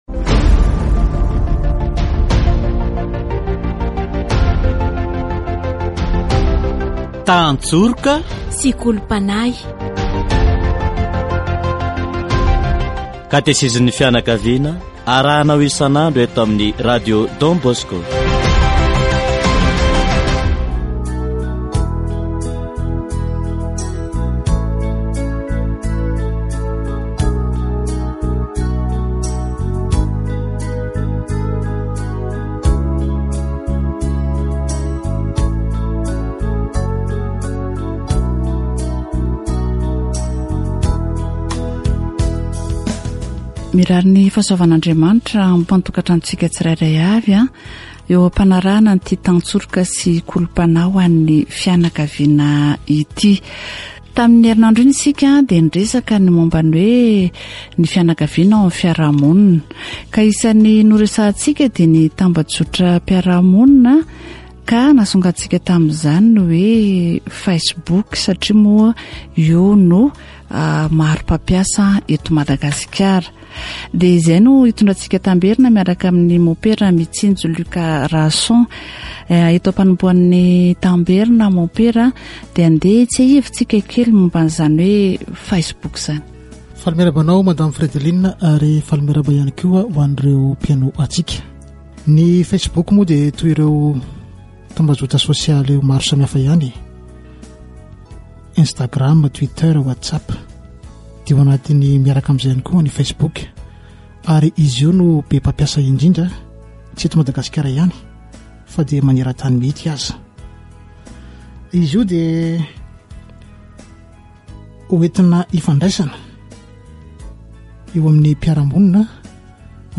Catechesis on Facebook and the internet